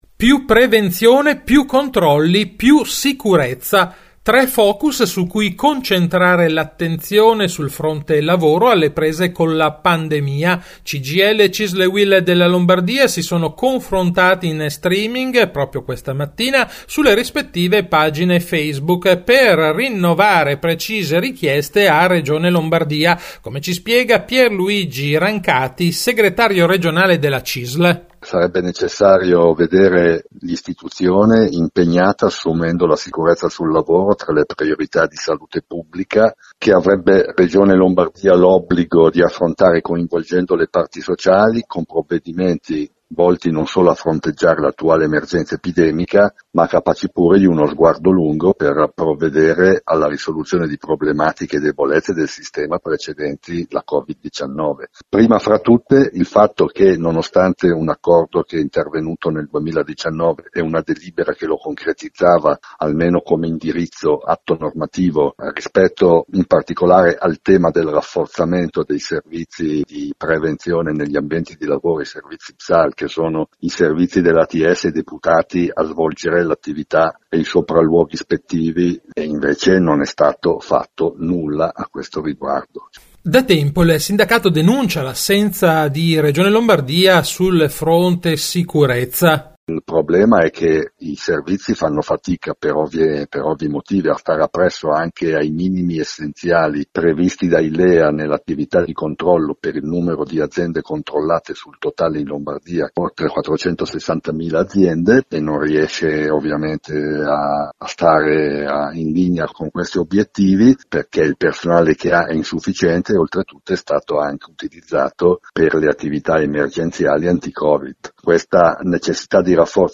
Di seguito la puntata del 27 novembre di RadioLavoro, la rubrica d’informazione realizzata in collaborazione con l’ufficio stampa della Cisl Lombardia e in onda ogni quindici giorni su RadioMarconi il venerdì alle 12.20, in replica alle 18.10.